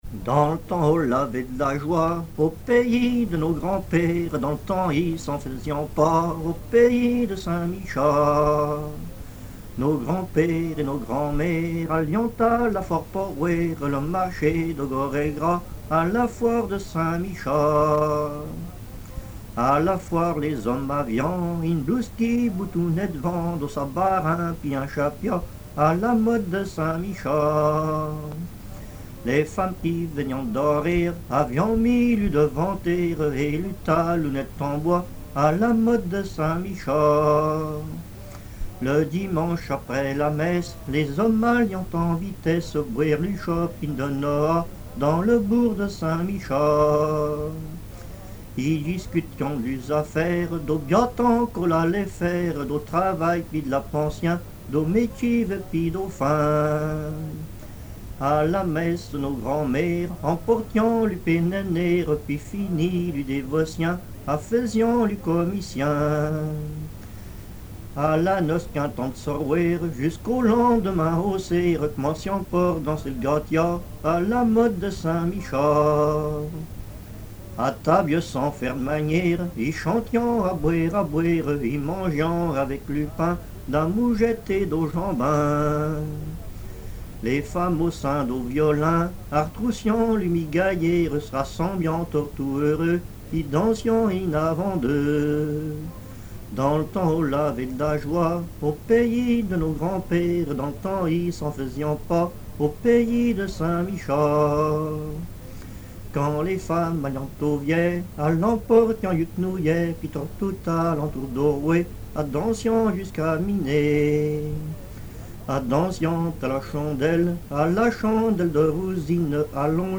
Localisation Saint-Michel-Mont-Mercure
Catégorie Pièce musicale inédite